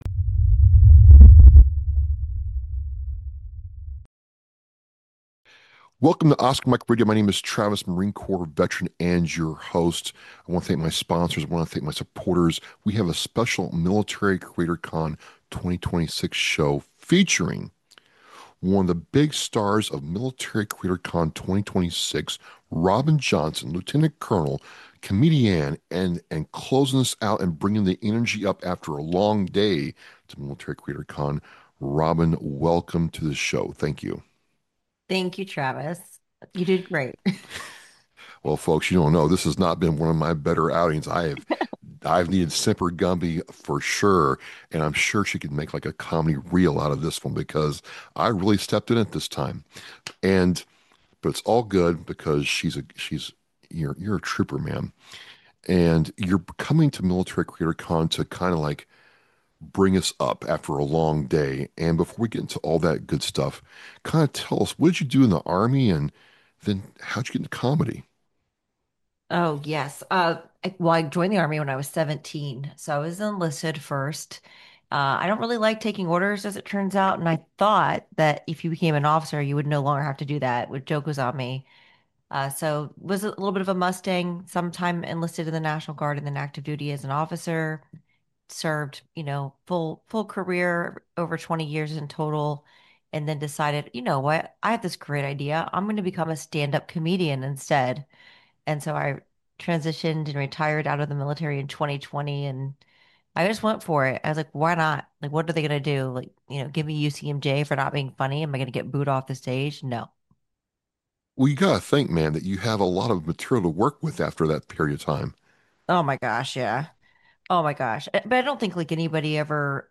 Why Humor Matters — Especially for Veterans One of the most powerful parts of our conversation was exploring how humor can help us heal.